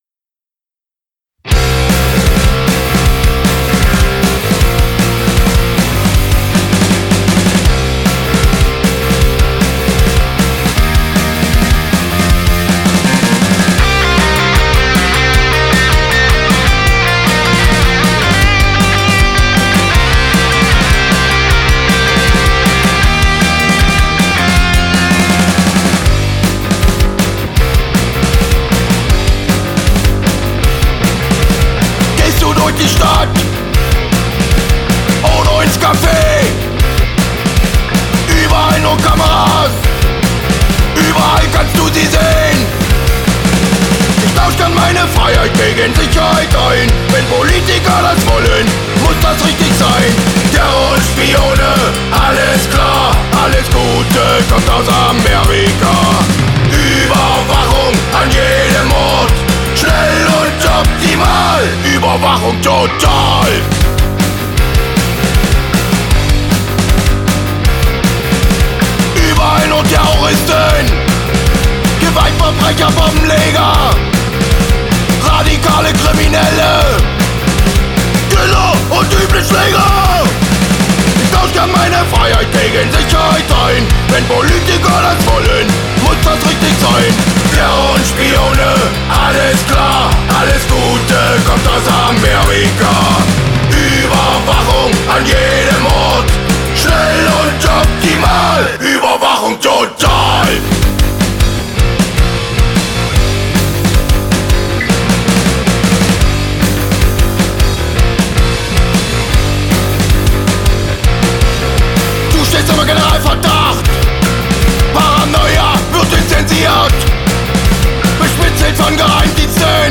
Zackiger Oi! mit einer Prise Punk